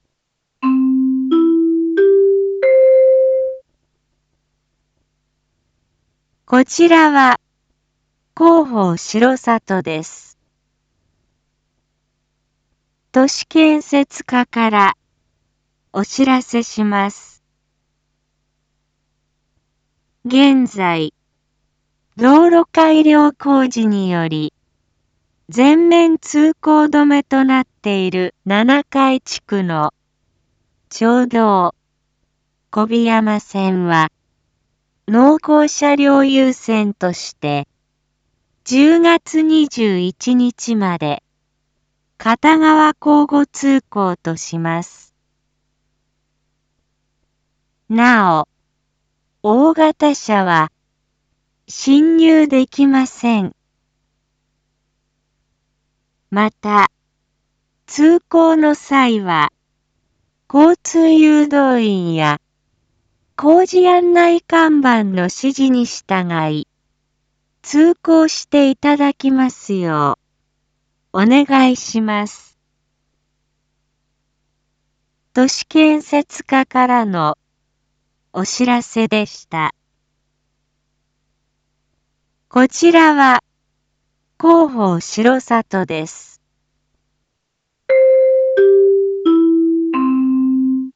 Back Home 一般放送情報 音声放送 再生 一般放送情報 登録日時：2022-10-19 19:01:32 タイトル：町道４号線 交通規制について（七会地区限定） インフォメーション：こちらは広報しろさとです。